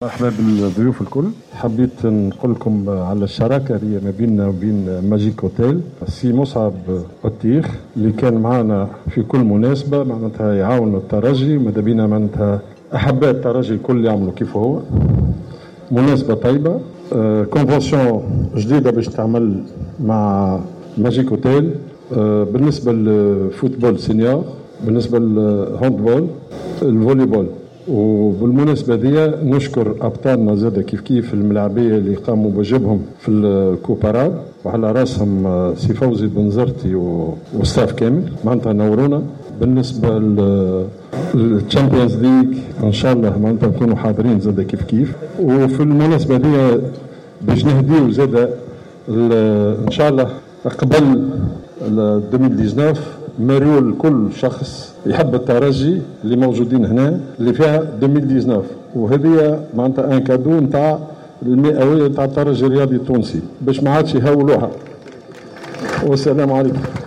أقام فريق الترجي الرياضي التونسي ليلة البارحة حفل إستقبال على شرف الإطار الفني ولاعبي الفريق المتوجين بالبطولة العربية للأندية لكرة القدم وهو حفل تزامن أيضا مع الإعلان عن توقيع عقد شركة مع إحدى الشركات المختصة في الفندقة والسياحة.